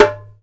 DUMBEK 6A.WAV